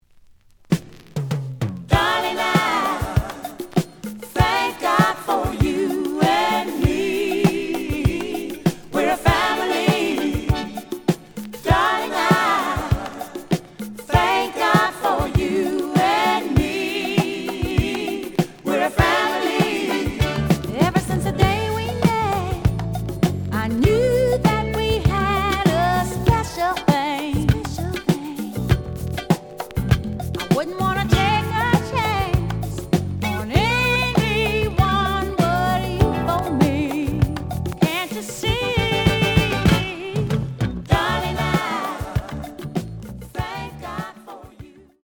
The audio sample is recorded from the actual item.
●Format: 7 inch
●Genre: Disco